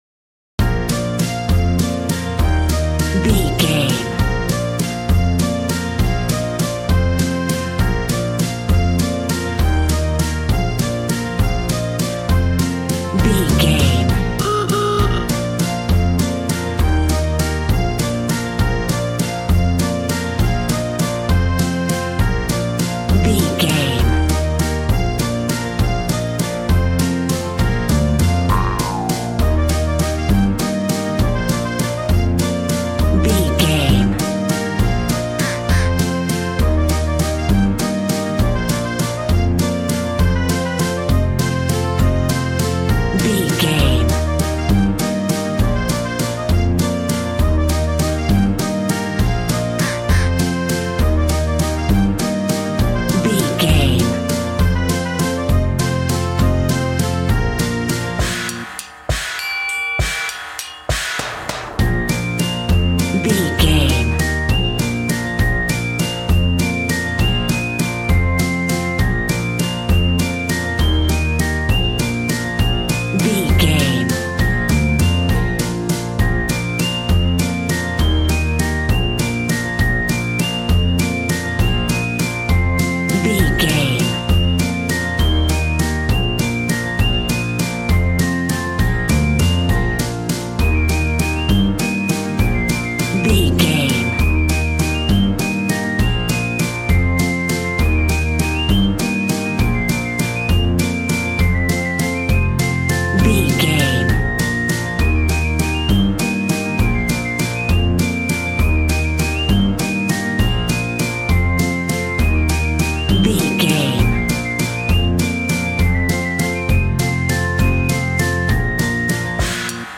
Ionian/Major
B♭
cheerful/happy
bouncy
electric piano
electric guitar
drum machine